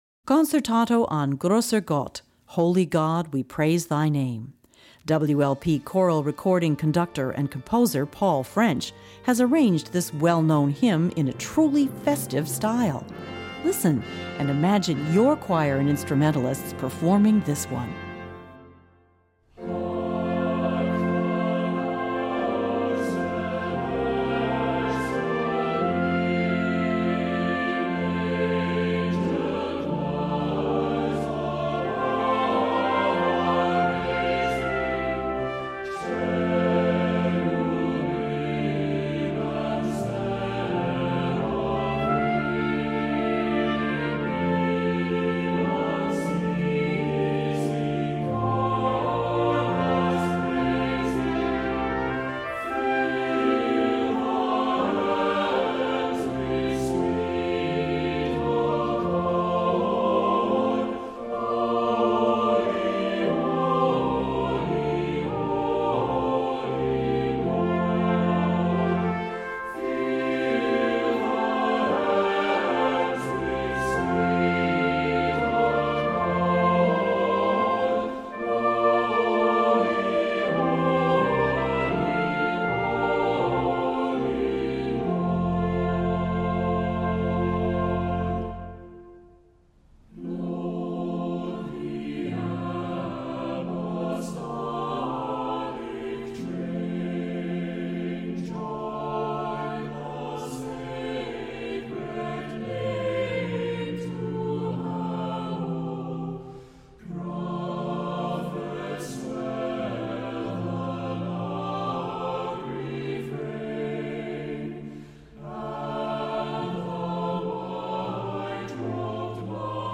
Voicing: SATB; Descant; Assembly